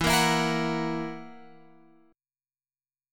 E Suspended 2nd Flat 5th